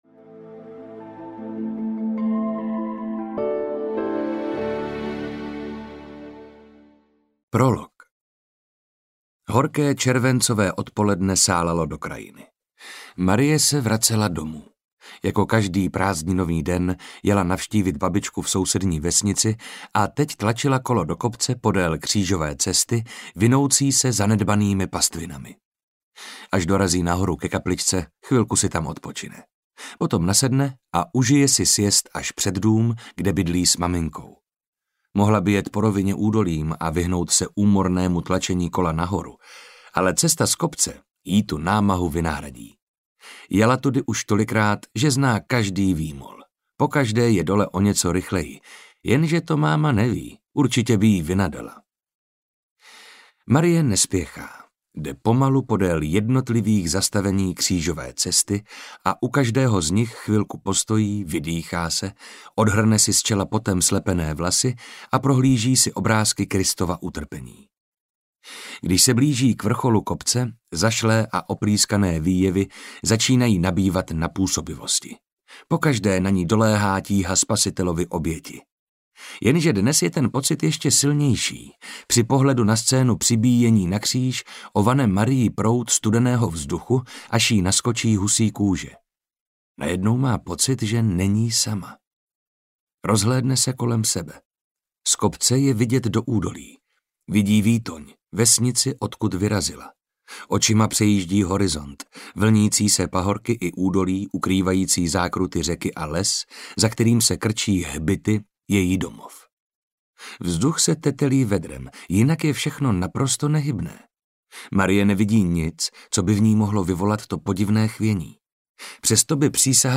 Na kopci audiokniha
Ukázka z knihy
• InterpretMarek Holý